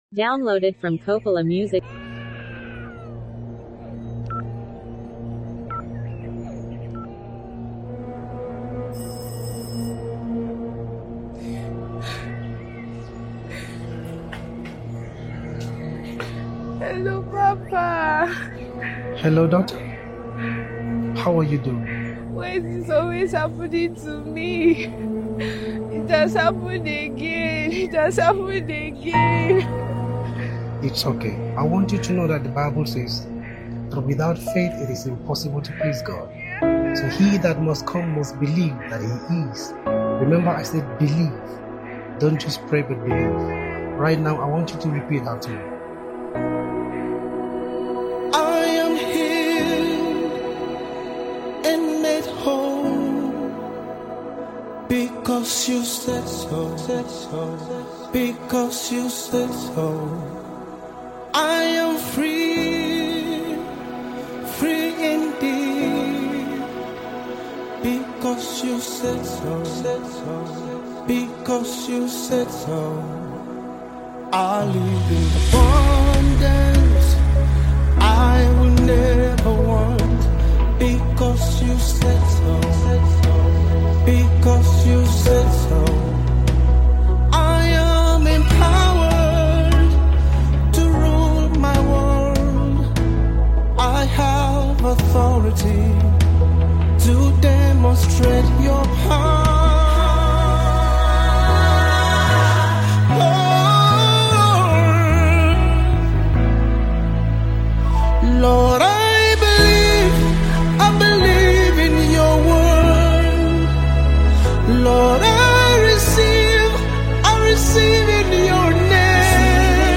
gospel worship song